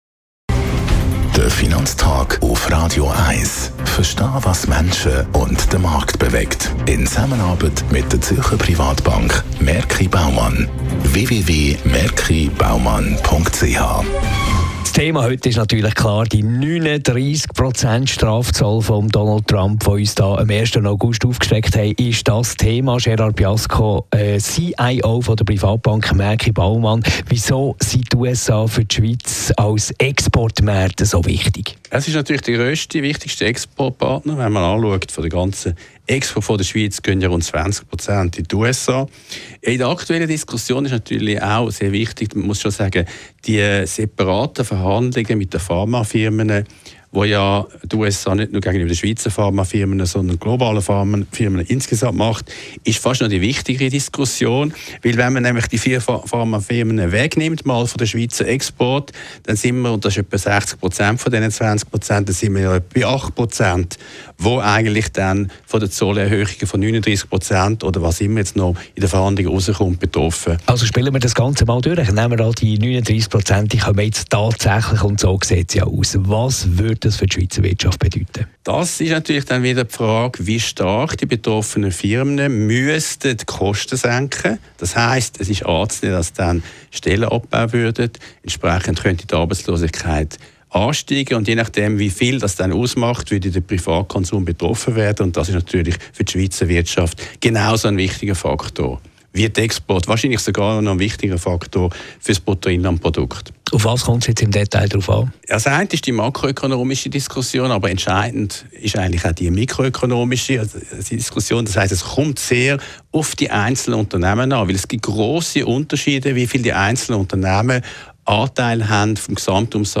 Unser wöchentlicher Finanz Podcast lässt Finanzexperten im Radio zu Wort kommen, die über wertvolle Einschätzungen für Anleger und Konsumenten verfügen.